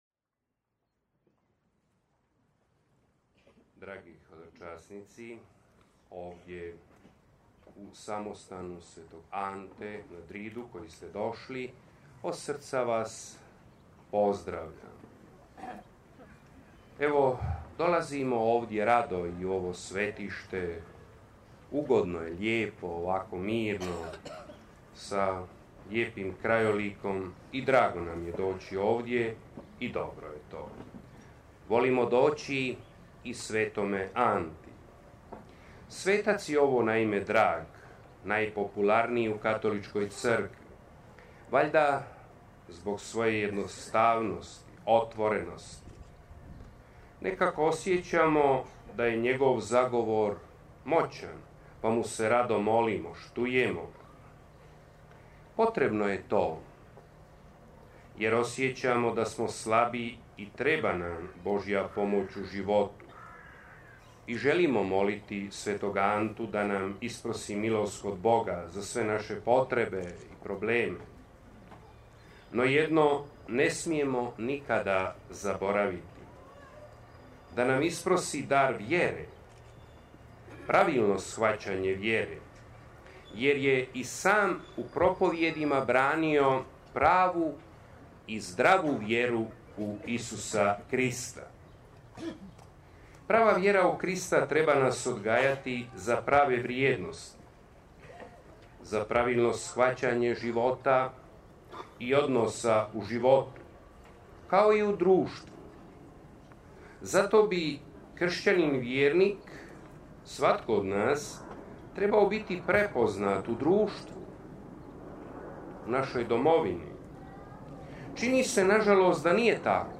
Nije li vrijeme da se kaže Hrvatska je poludivlja, polunormalna ili polunenormalna koji su problemi i što je dovelo do toga i što je još važnije kako bi se kršćanin trebao ponašati u svemu tome i koji bi kršćanski stav trebao imati. Propovjed govori o tome.
sv. Antun Padovanski – samostan i svetište sv. Ante na Dridu 13.06.2015